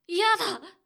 ボイス
リアクション中性